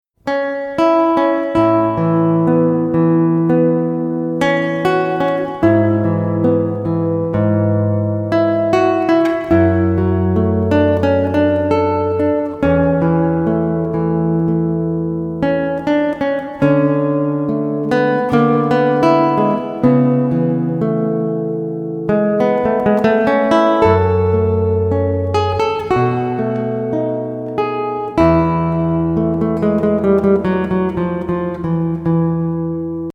eseguite con una sola chitarra